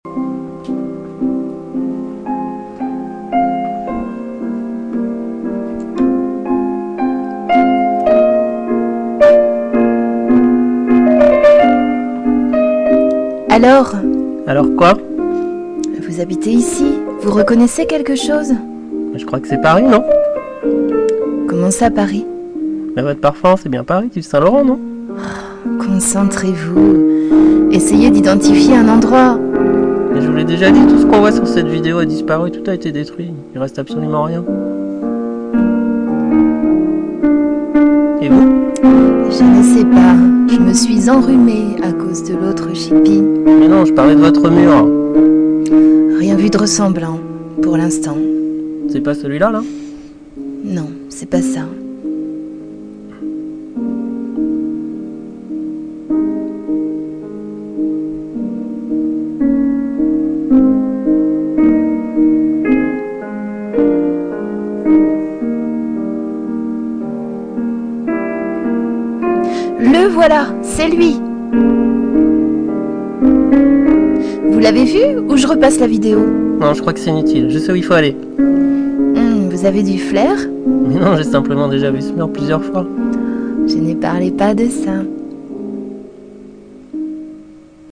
Il se sentait bien ainsi, bercé par cette musique de fond aux accents pathétiques.